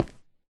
Sound / Minecraft / step / stone2